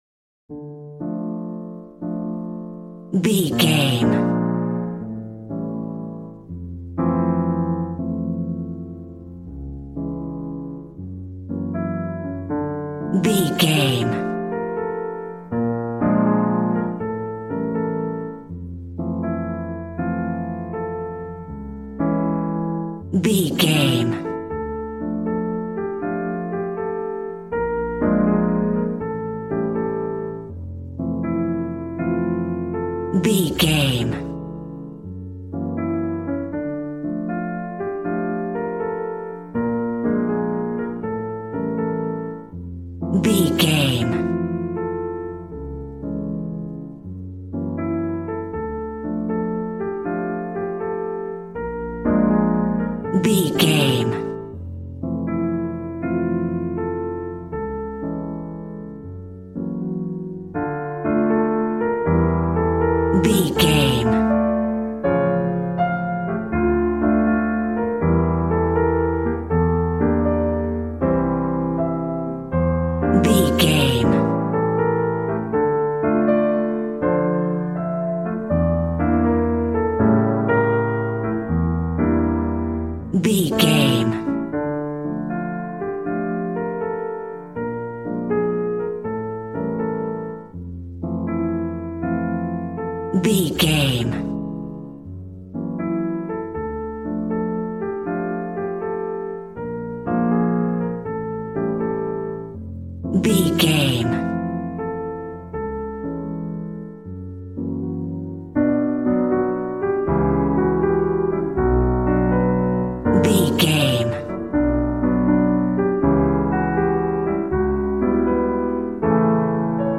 Aeolian/Minor